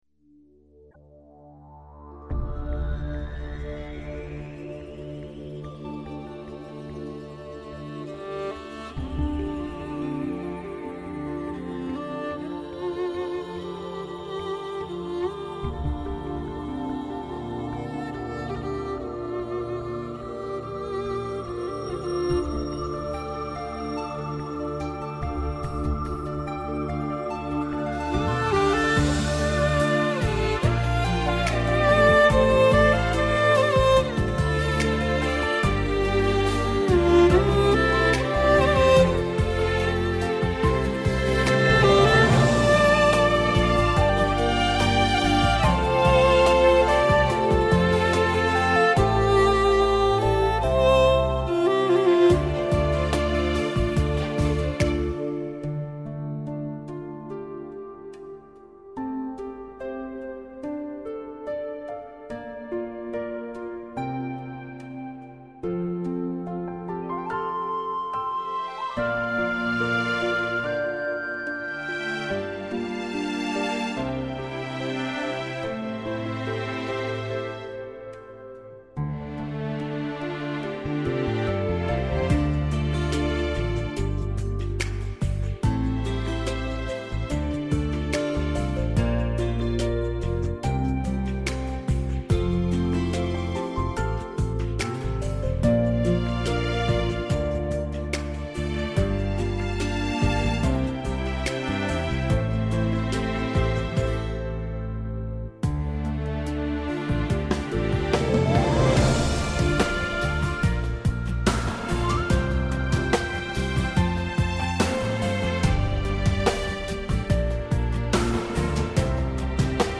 无 调式 : F 曲类